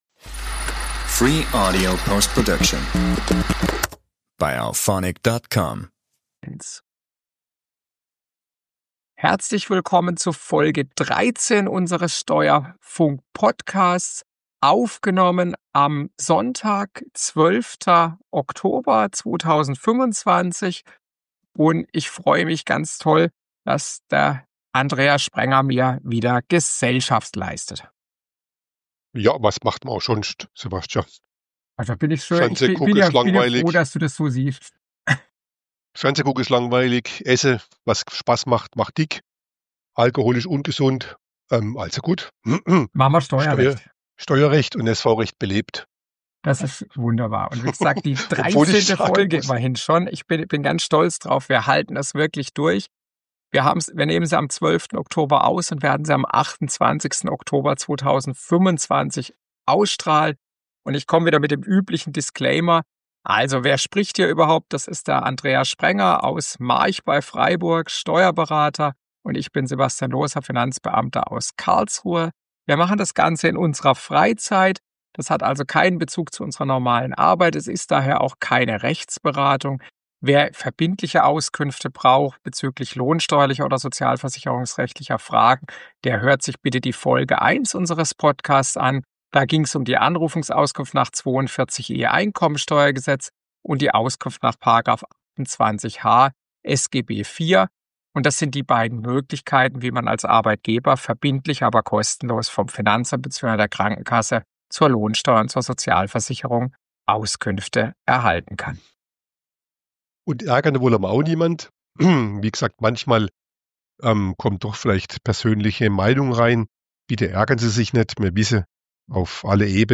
Dieses Mal haben wir an der Tonqualität gearbeitet - wir hoffen, das gefällt!